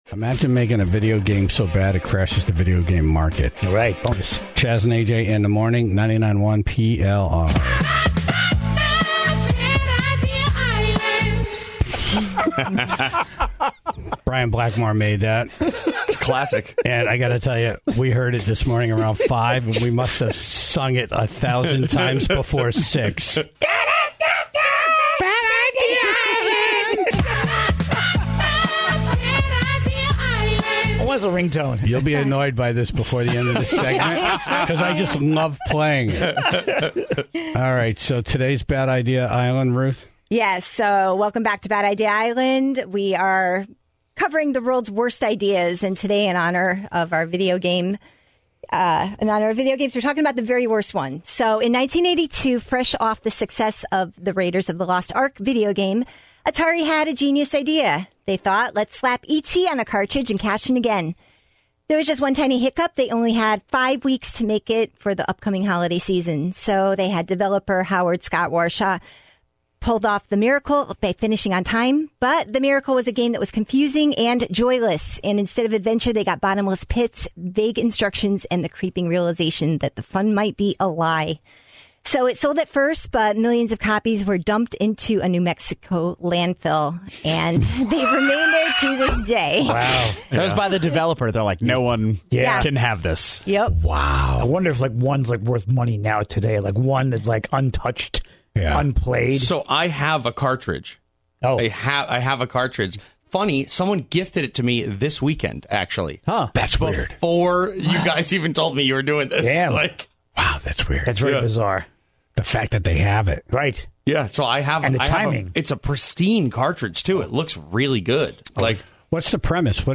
was on the phone
was in studio